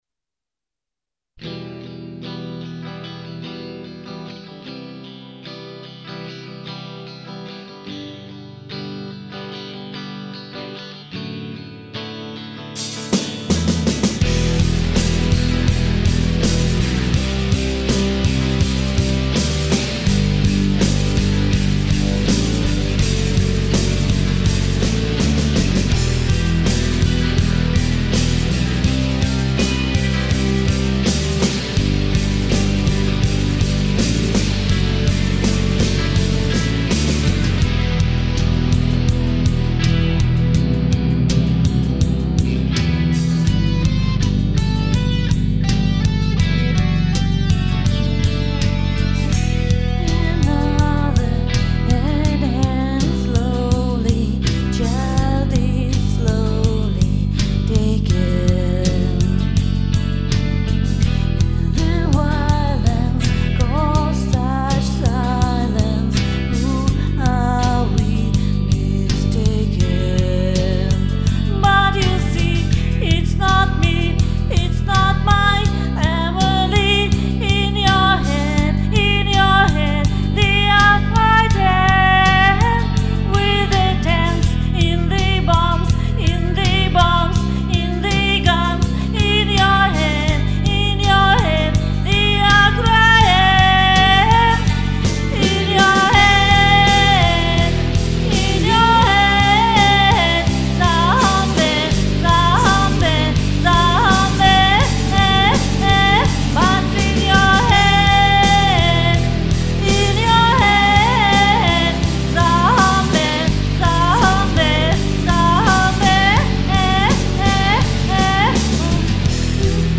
P.S. в начале 50 сек проигрыша и в конце полторы минуты